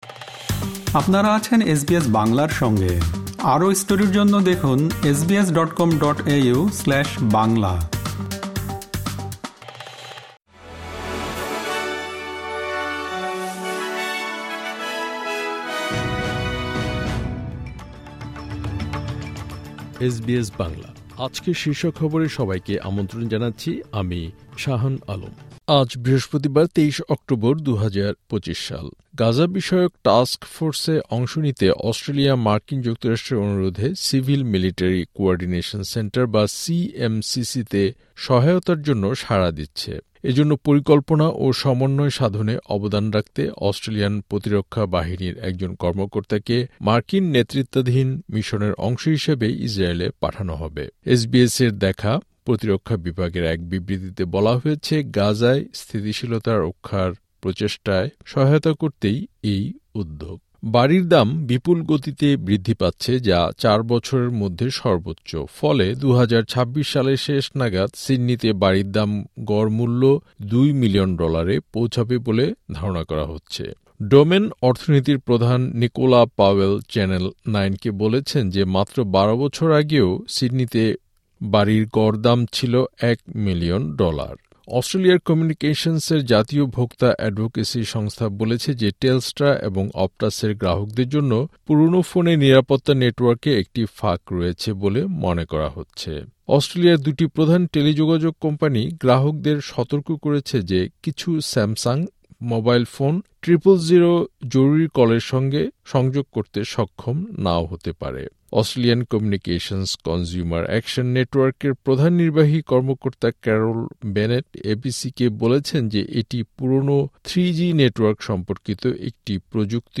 এসবিএস বাংলা শীর্ষ খবর: ২৩ অক্টোবর, ২০২৫